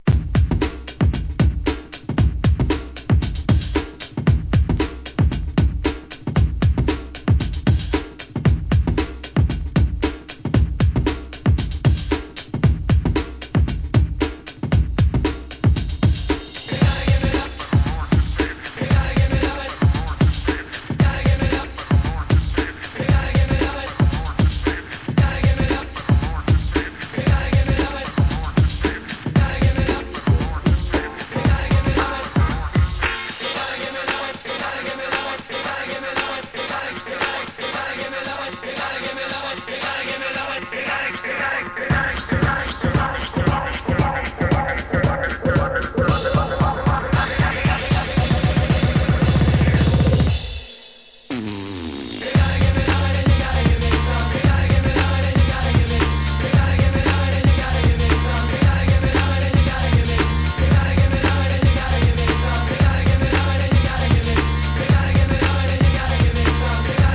HIP HOP/R&B
EXTENDED MIX